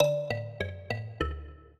mbira
minuet15-12.wav